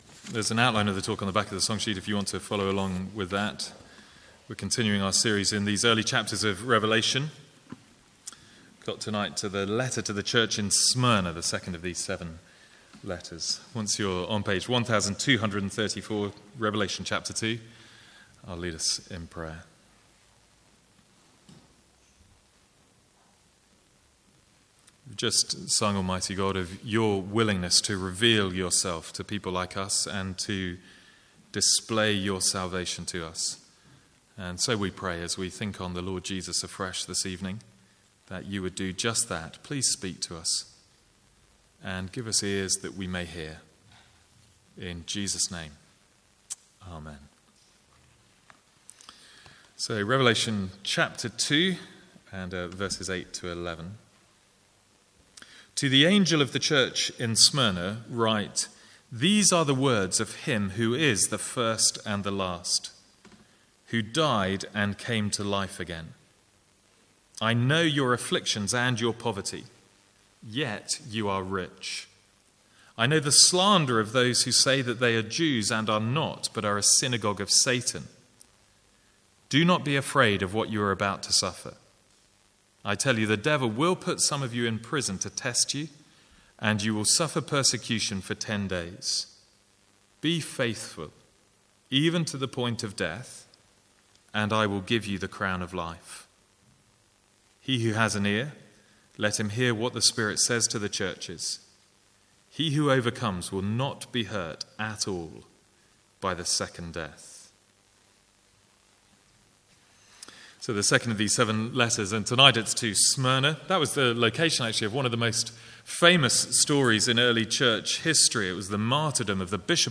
Sermons | St Andrews Free Church
From the Sunday evening series in Revelation.